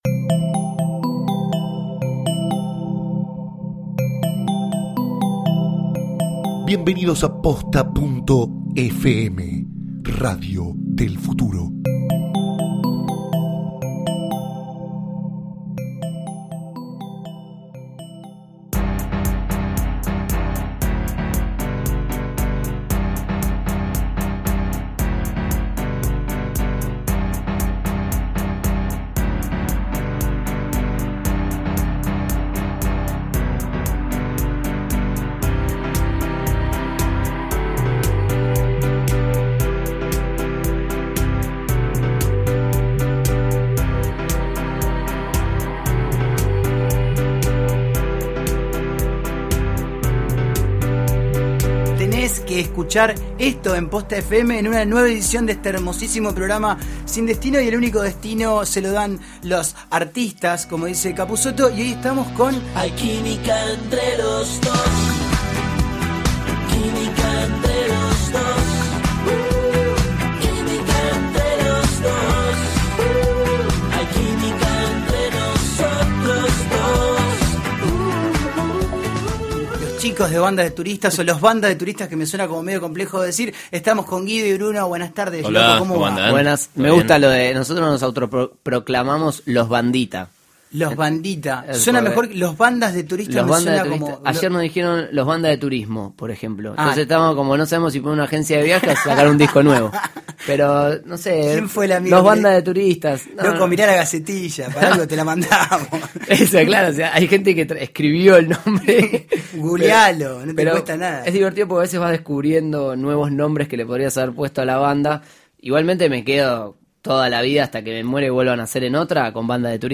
recibe en el estudio a los talentosos jóvenes de Banda de Turistas